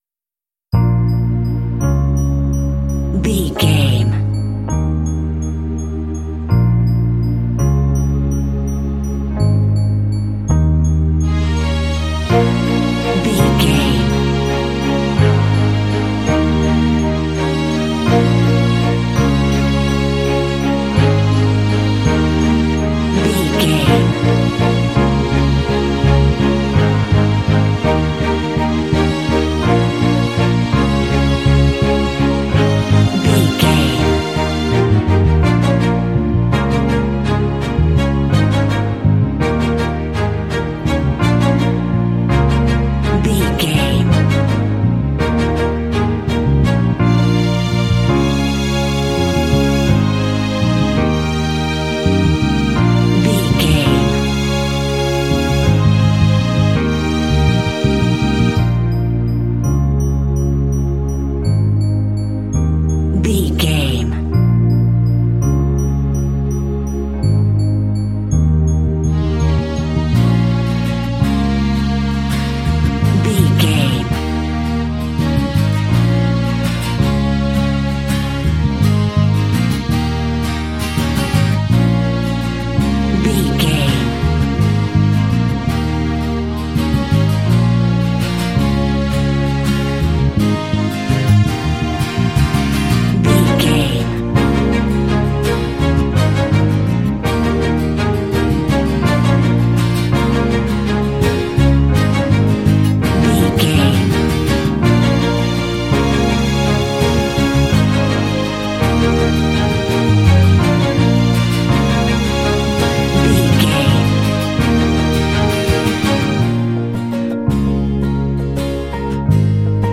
Mixolydian
B♭
fun
bright
lively
sweet
drum machine
piano
strings
synthesiser
acoustic guitar
cinematic
pop